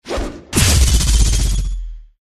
GameMpassetsMinigamesCjsnowEn_USDeploySoundGameplaySfx_mg_2013_cjsnow_attackpowercardsnow.mp3